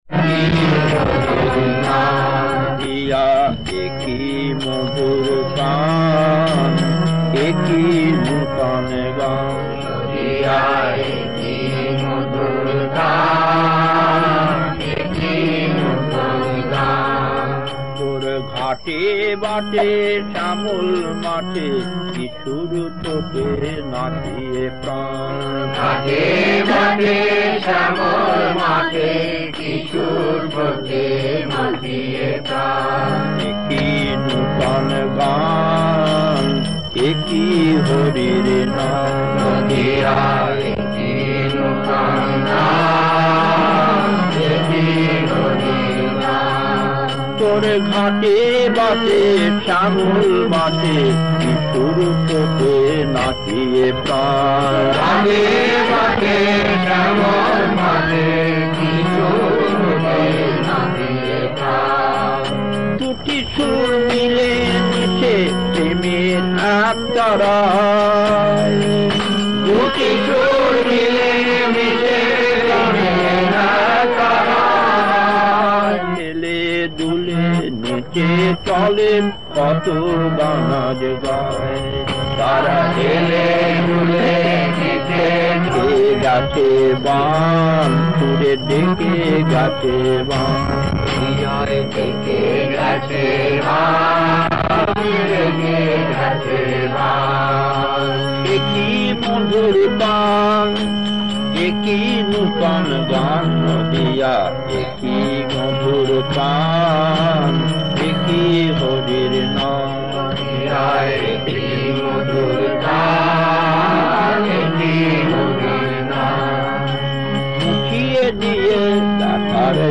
Kirtan B12-2 Puri 1979, 72 Minutes 1.